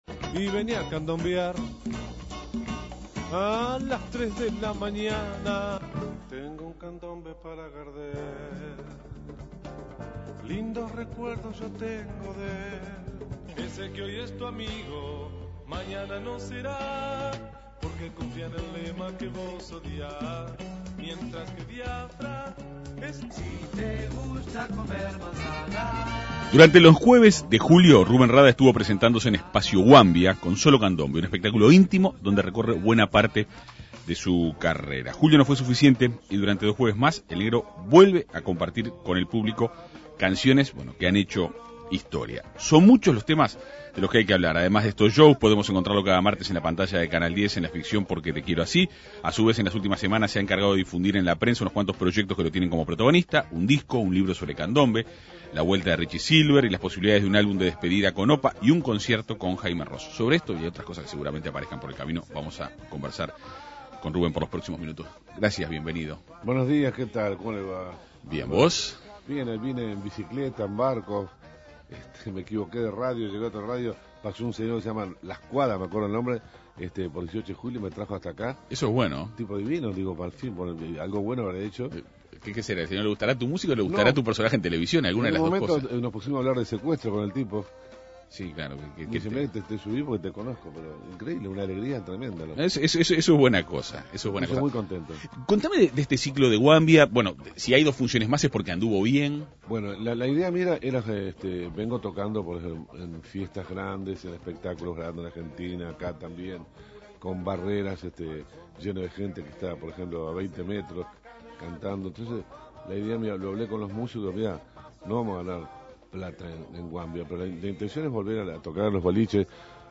El músico dialogó en la Segunda Mañana de En Perspectiva.